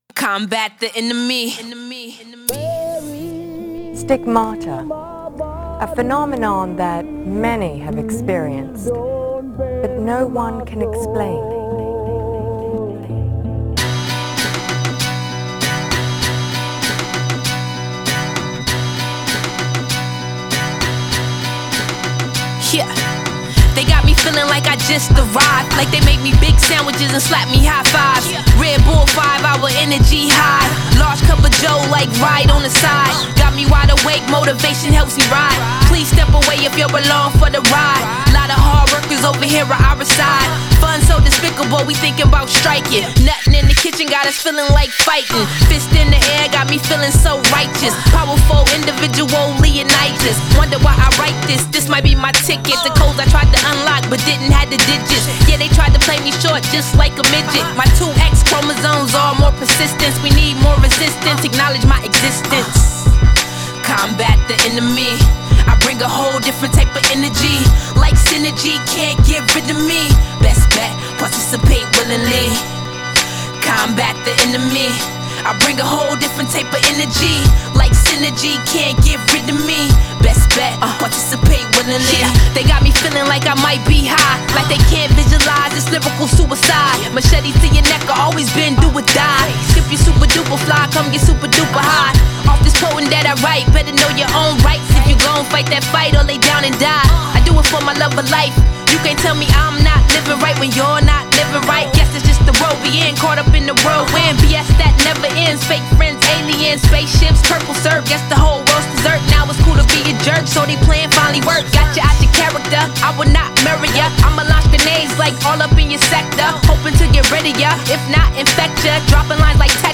emcee